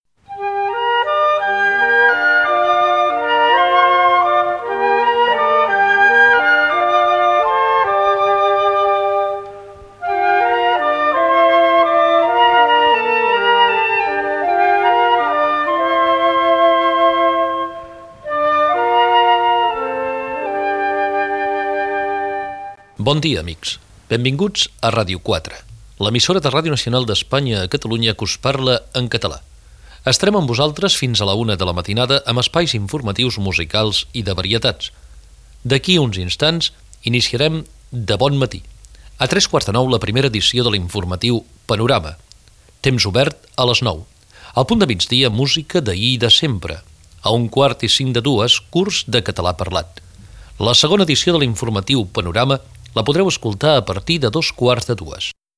Primer fragment: recreació del text original que es va llegir en obrir Ràdio 4.